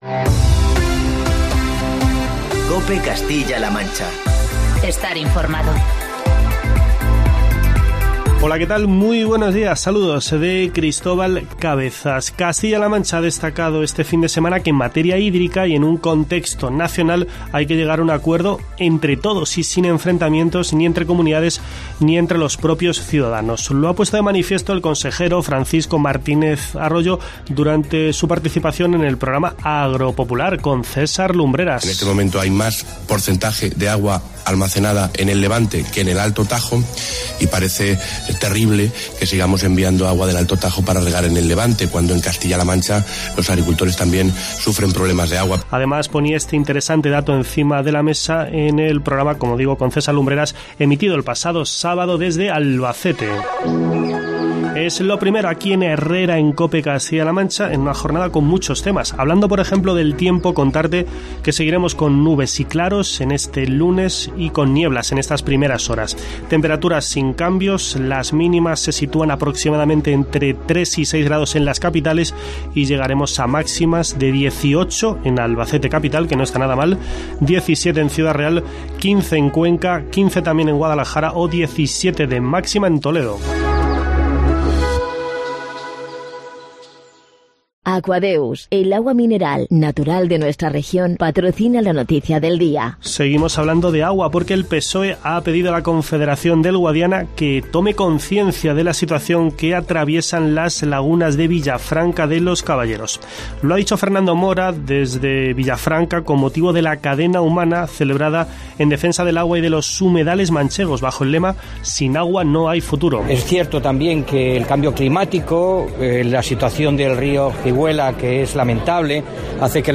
Escucha en la parte superior de esta noticia el informativo matinal de COPE Castilla-La Mancha y COPE Toledo.